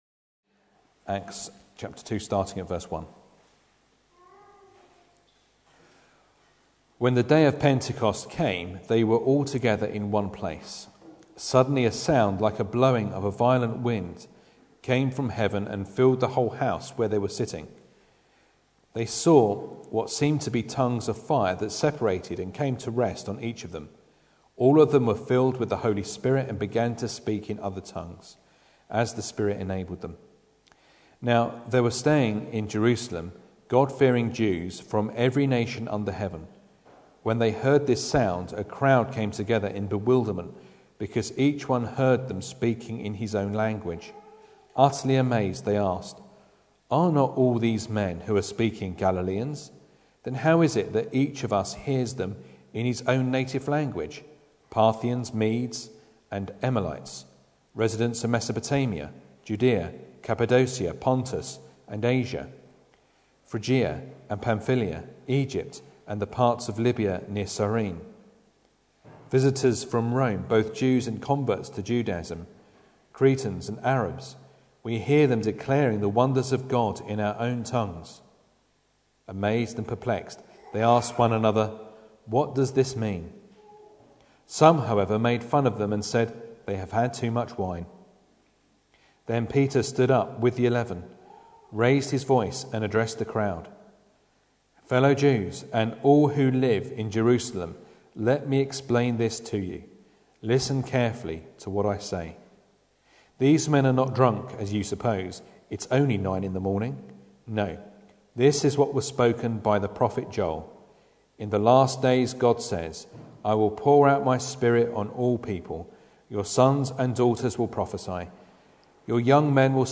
Acts 2:1-41 Service Type: Sunday Evening Bible Text